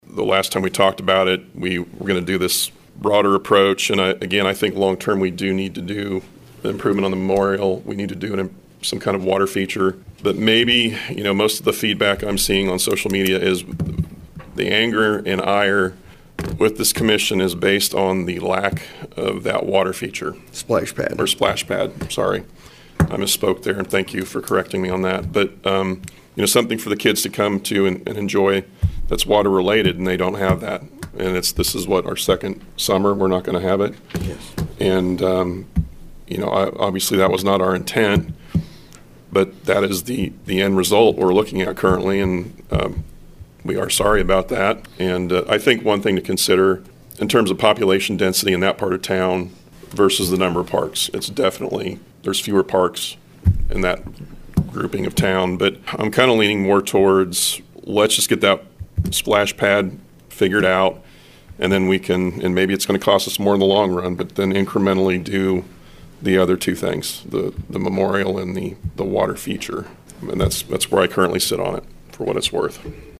On Monday, April 14th at the Salina City Commission Meeting, city staff and commissioners discussed plans for the existing splash pad at Jerry Ivey.
Mayor Greg Lenkiewicz commented on the feedback they have been receiving on social media, plus the plan moving forward.